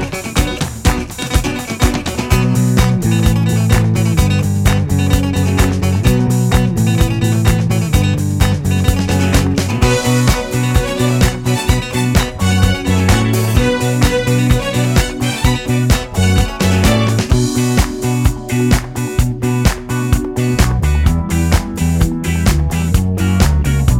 no Backing Vocals Disco 4:41 Buy £1.50